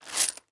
household
Coins in Plastic Bag Picking Up from Cement